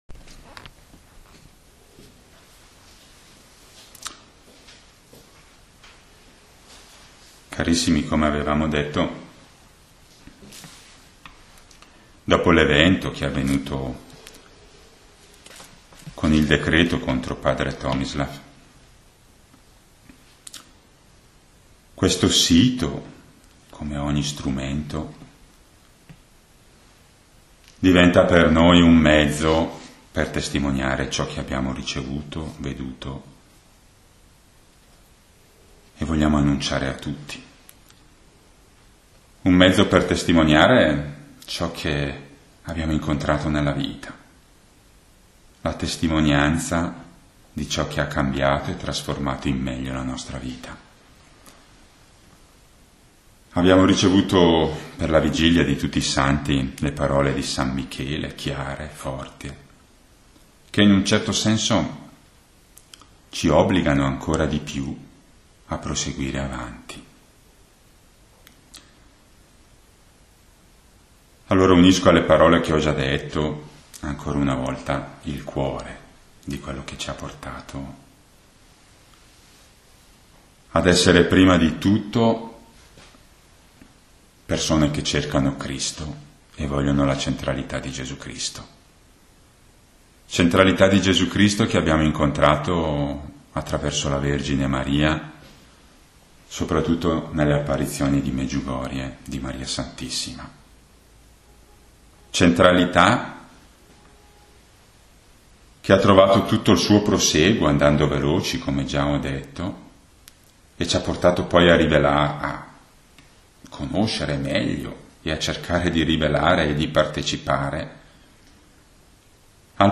Testimonianza - Chiesa di Gesù Cristo dell'Universo